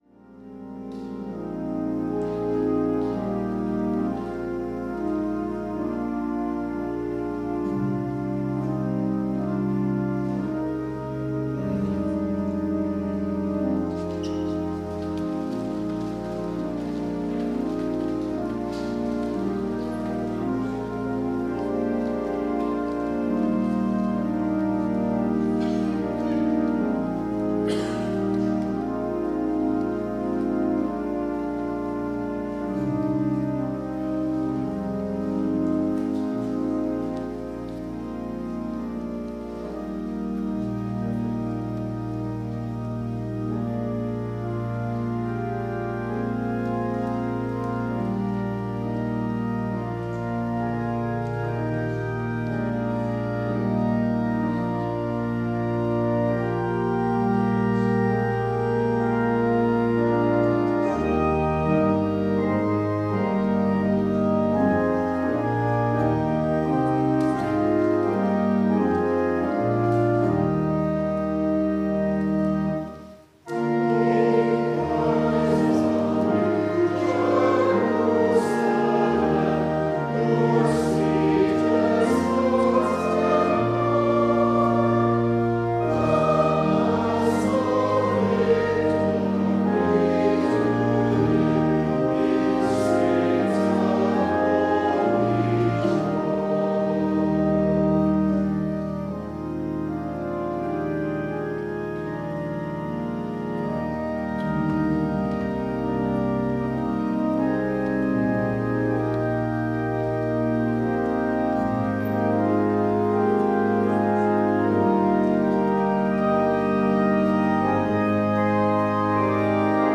Worship and Sermon audio podcasts
WORSHIP - 10:30 a.m. Fifth of Easter